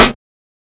MPC sn.wav